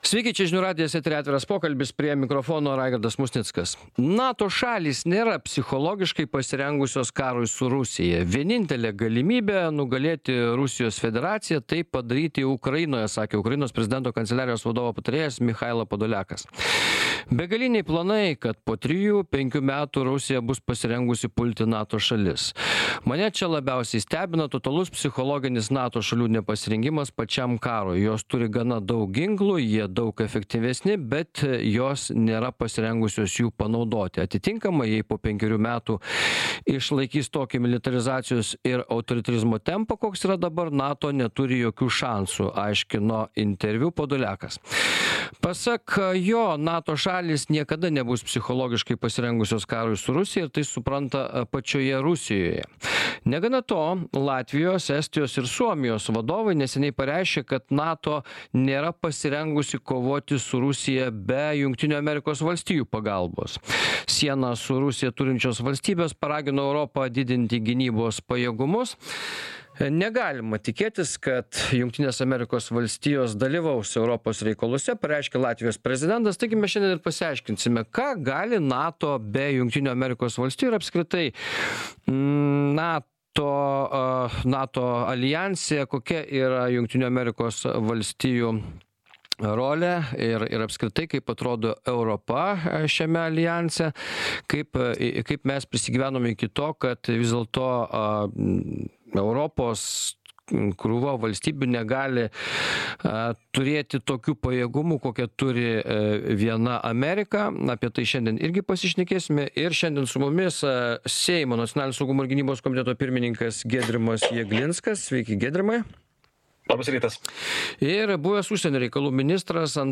Atviras pokalbis Žiūrėkite Klausykitės Atsisiųsti Atsisiųsti Ką gali NATO be JAV? 2025-01-07